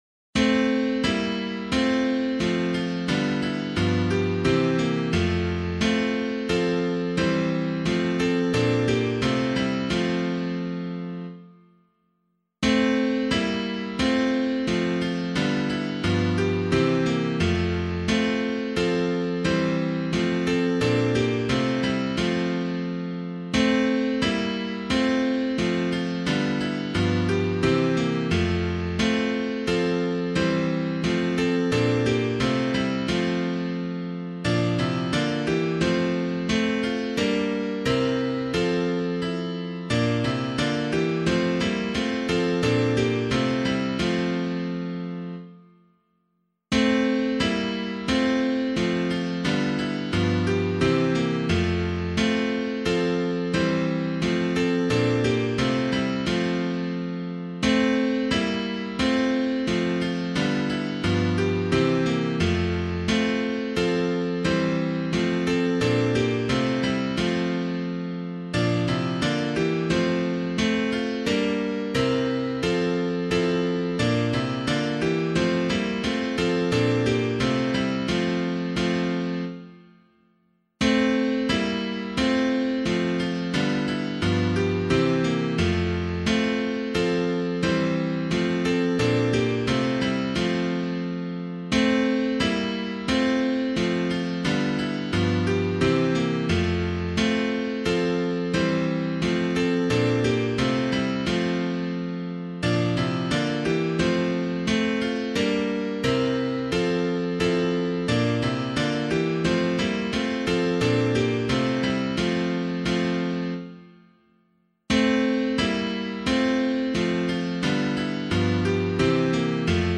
Meter:    87.87.77